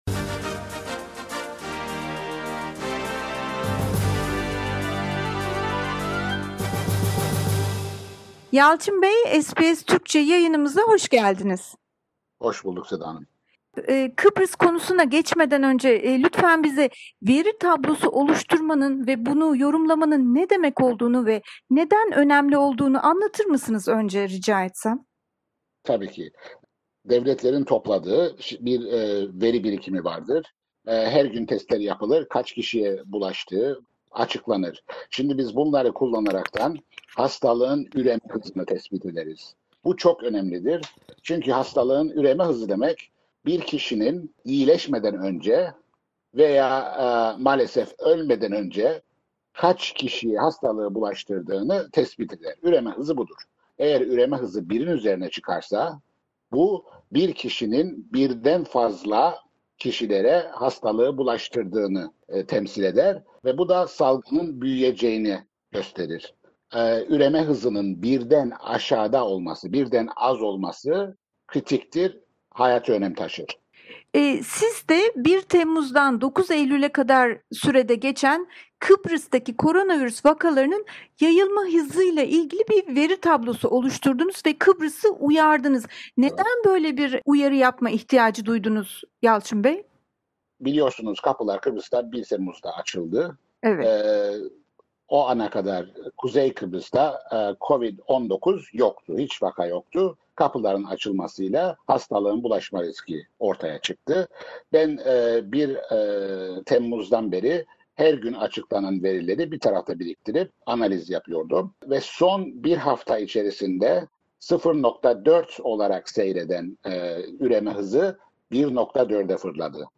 röportajımız